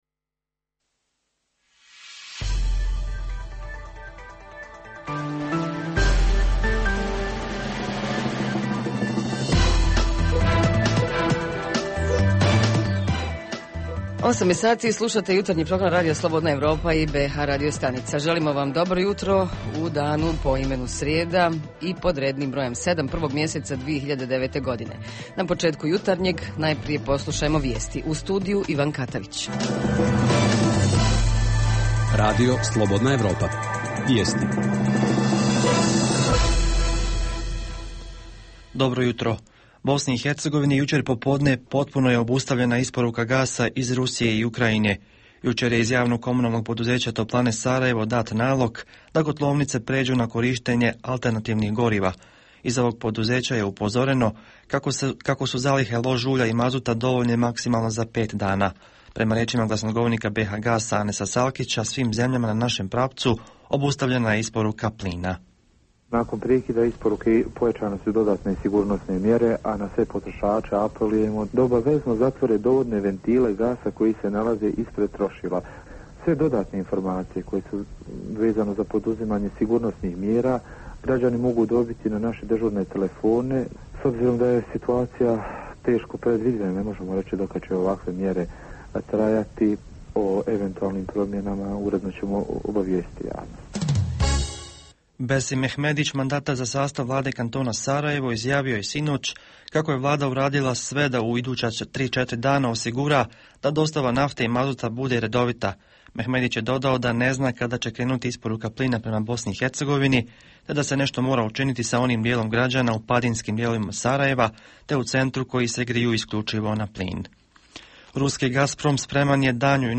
Jutarnji program za BiH koji se emituje uživo. Ovog jutra pitamo: kupujemo li domaće proizvode?!